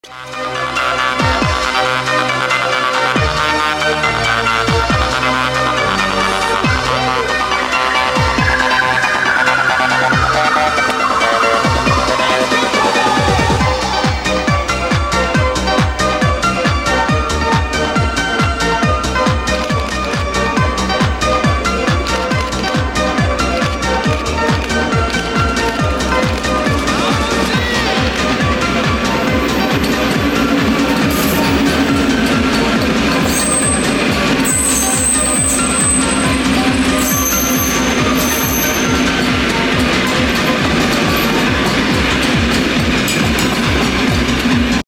E231 0 Series On Chūō–Sōbu Line Sound Effects Free Download